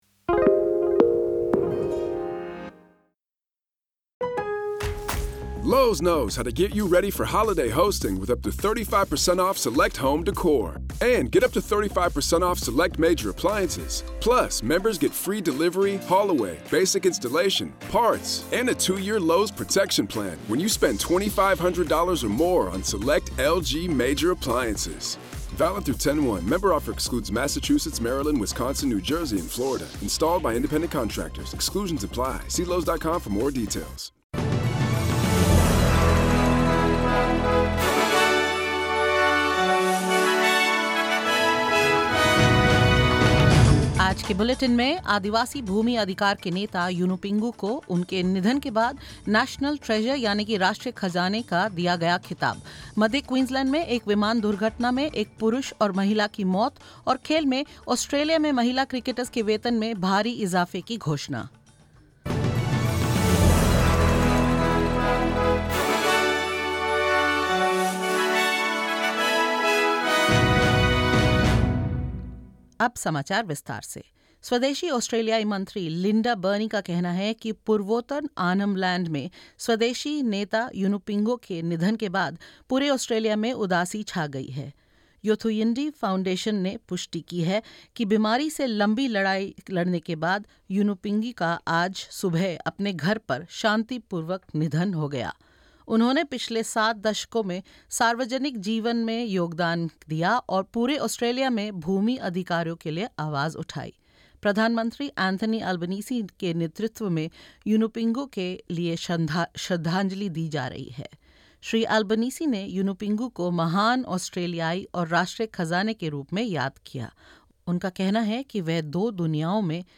In this latest Hindi bulletin: Prime Minister Anthony Albanese remembers Aboriginal land rights champion Yunupingu as "national treasure"; Two people have been confirmed dead in a light plane incident in central Queensland; Cricket Australia announces multi-million-dollar deal for the women's game and the Big Bash League, and more.